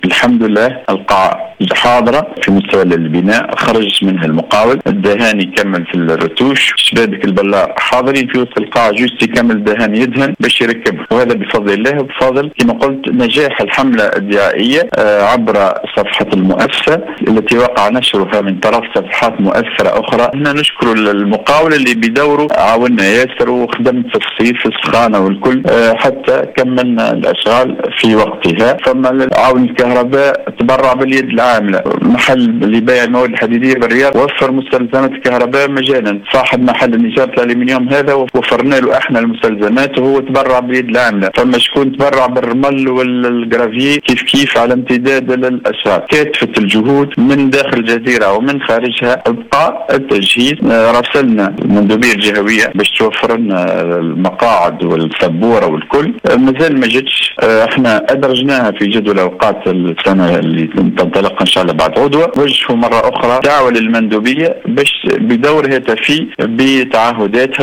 وقال المتحدث، في تصريح لأوليس اف ام صباح اليوم، انه حيث تم ادراج هذه القاعة في جدول الاوقات للسنة الدراسية الجديدة، داعيا مندوبية التربية الى الايفاء بتعهداتها والاسراع بتجهيزها حتى تكون جاهزة لاستقبال التلاميذ في مفتتح السنة الدراسية (تسجيل)